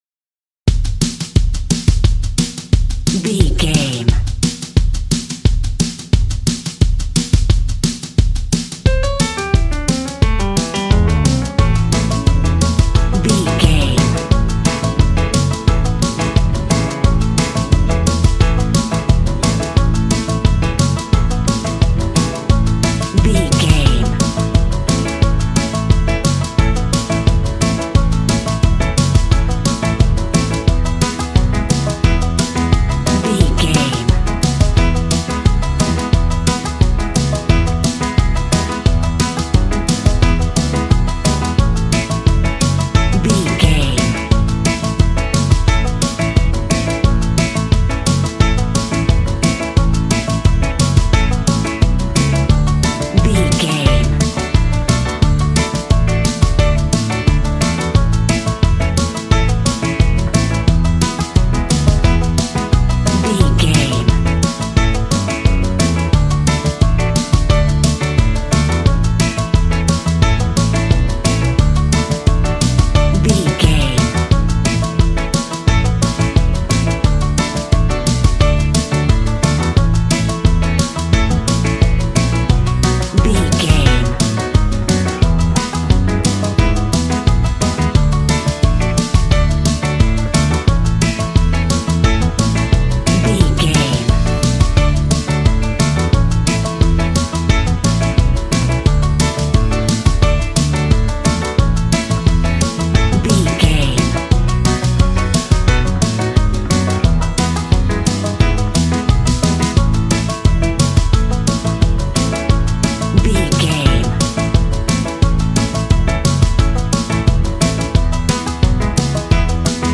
Country music for a cowboy rodeo show.
Ionian/Major
Fast
bouncy
double bass
drums
acoustic guitar